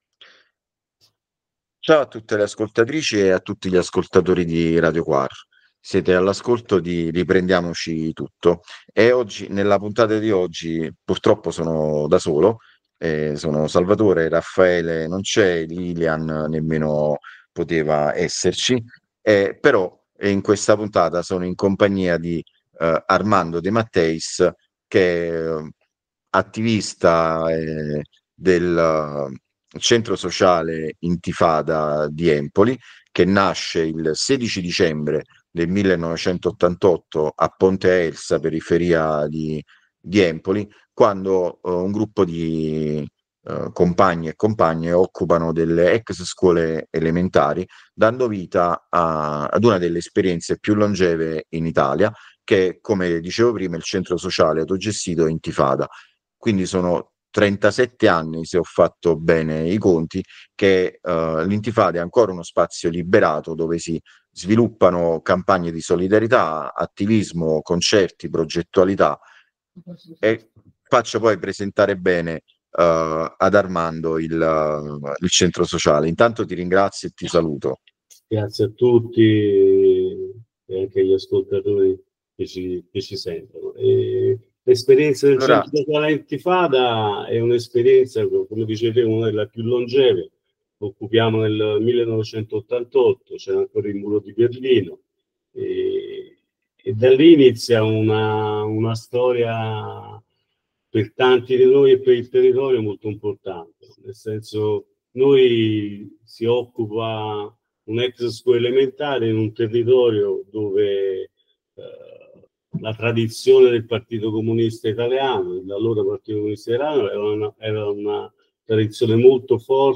s01e14-riprendiamoci-tutto-intervista-csa-intifada-empoli.mp3